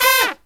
FALL HIT02-R.wav